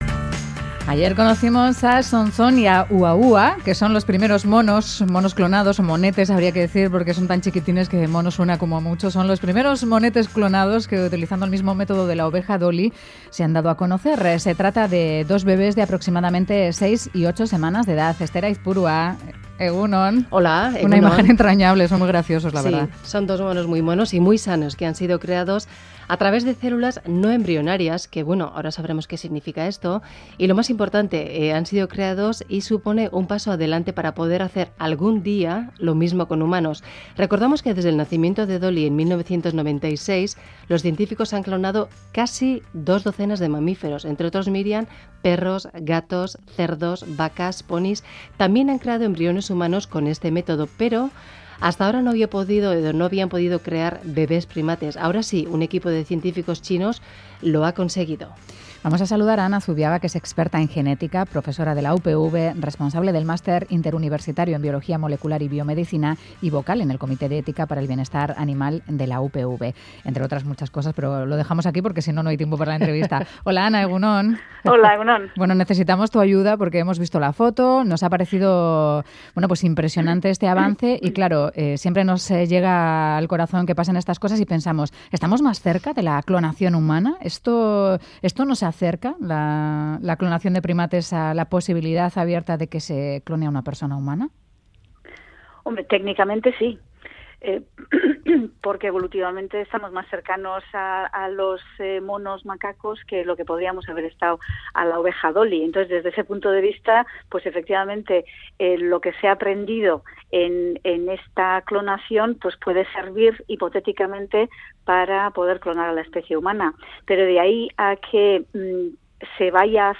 Audio: Clonacón de monos. Estudios biomédicos en Parkinson. Entrevista